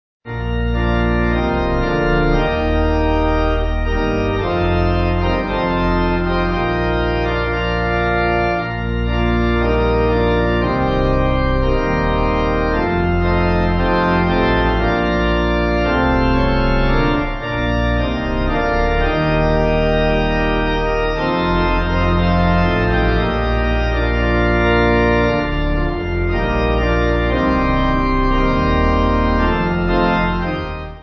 (CM)   3/Bb